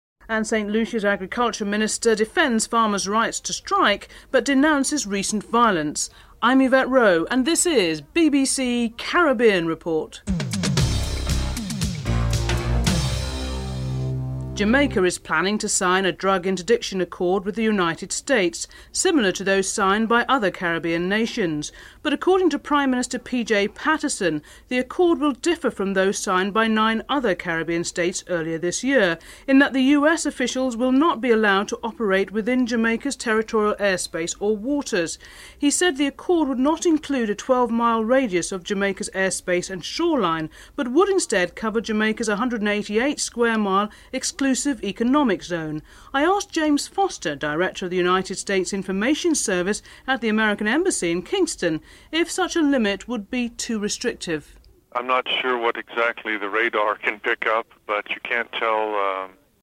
1. Headlines (00:00-00:13)
5. St. Lucia's Agriculture Minister defends farmers rights to strike but denounces recent violence. Minister of Agriculture Peter Josie is interviewed (11:38-12:57)